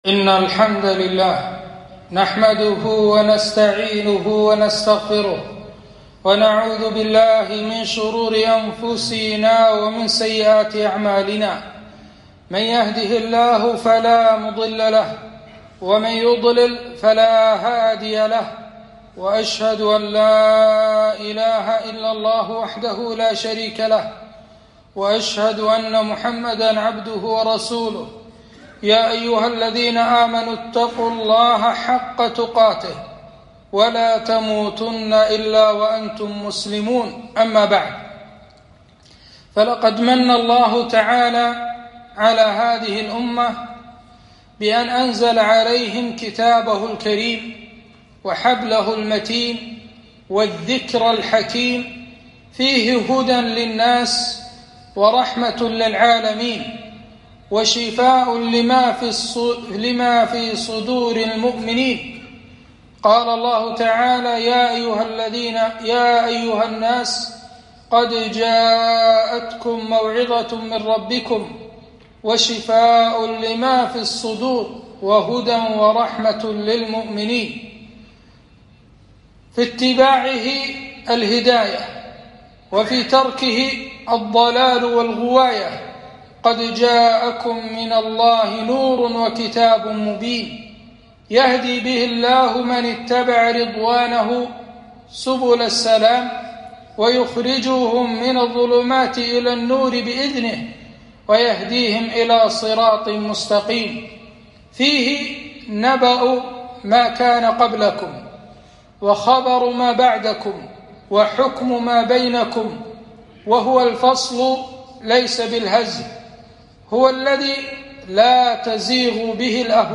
خطبة - أهل القرآن